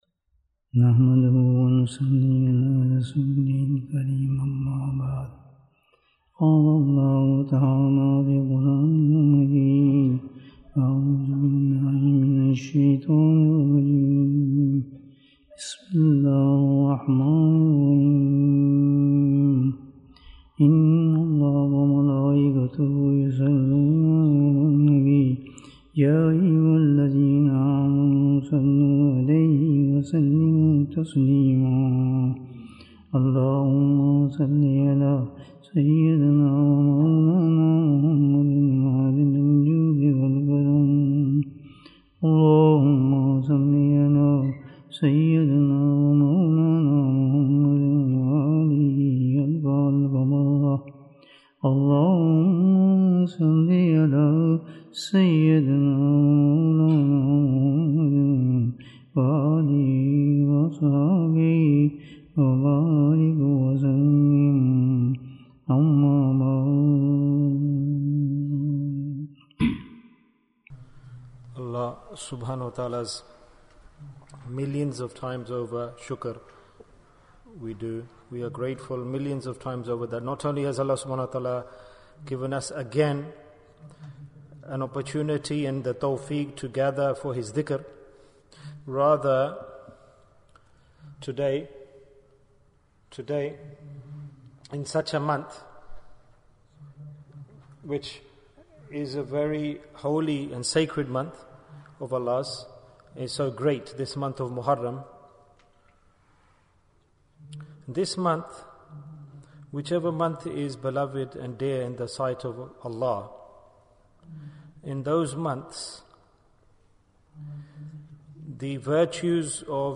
Why is Dhikr Very Important? Bayan, 40 minutes4th August, 2022